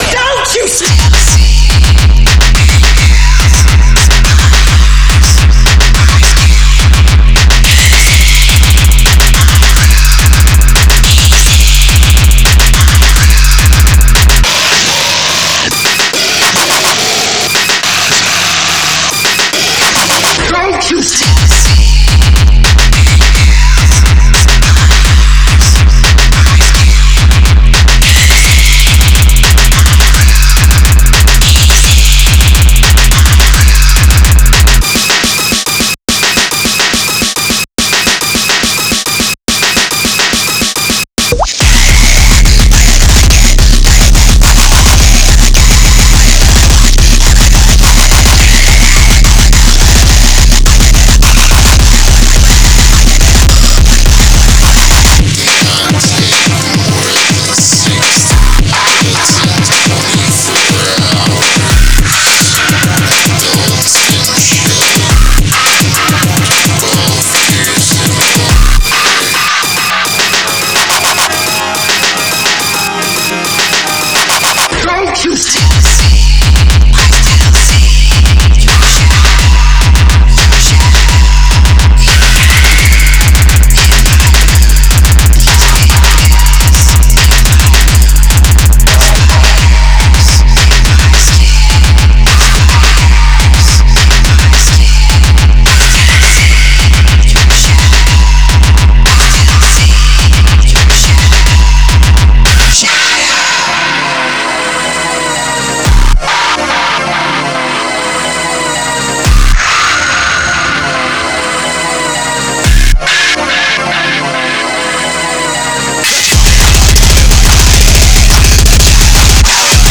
noise, gabber, digital hardcore, breakcore, ,